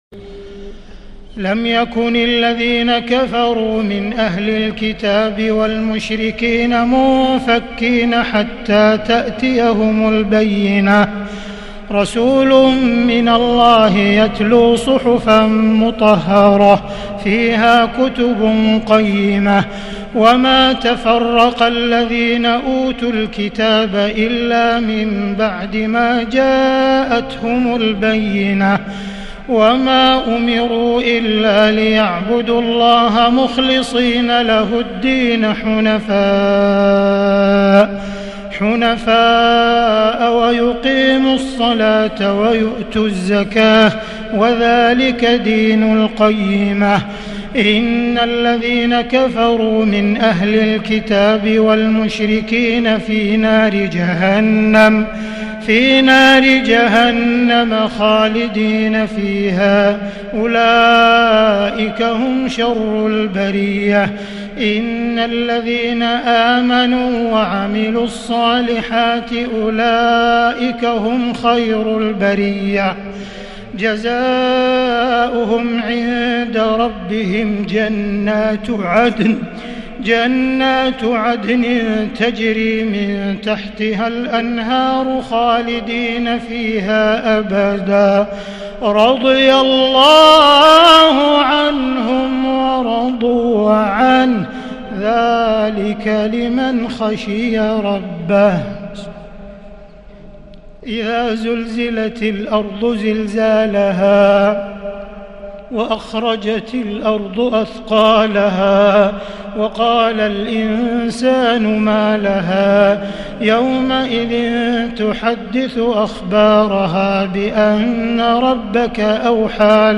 تهجد ليلة 29 رمضان 1441هـ من سورة البينة حتى سورة الناس | Tahajjud on the night of Ramadan 29, 1441 AH From Surat Al-Bayyina to Surat Al-Nas > تراويح الحرم المكي عام 1441 🕋 > التراويح - تلاوات الحرمين